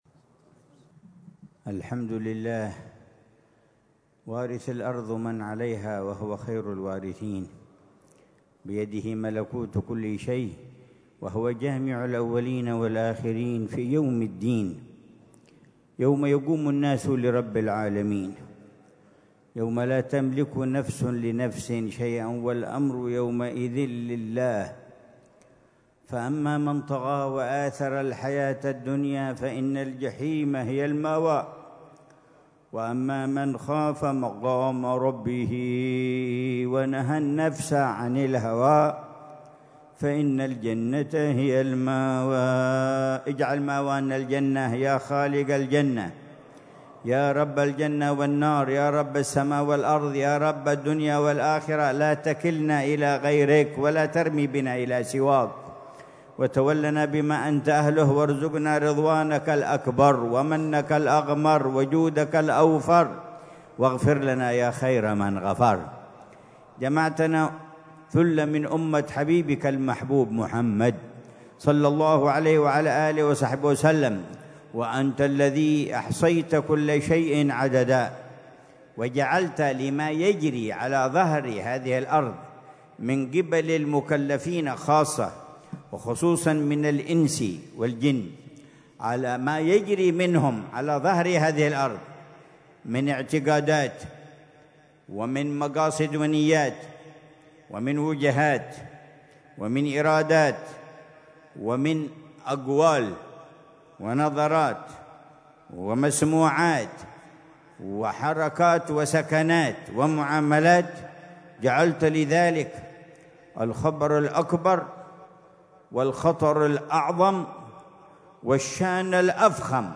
محاضرة العلامة الحبيب عمر بن محمد بن حفيظ ضمن سلسلة إرشادات السلوك، ليلة الجمعة 30 محرم 1447هـ في دار المصطفى بتريم، بعنوان: